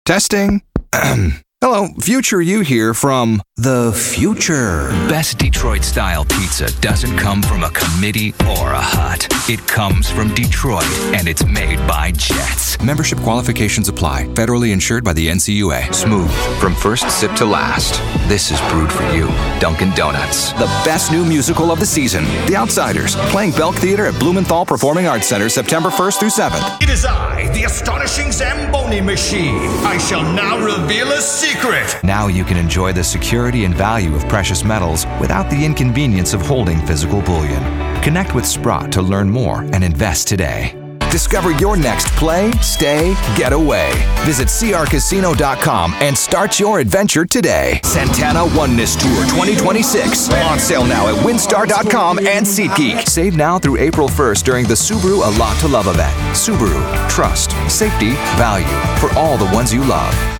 Commercial Demo
commercial-demo.mp3